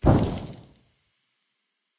sounds / mob / zombie / infect.ogg
infect.ogg